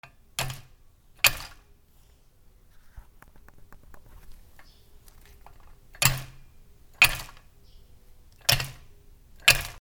扉
/ K｜フォーリー(開閉) / K05 ｜ドア(扉)
クレール 『キ』